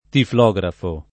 [ tifl 0g rafo ]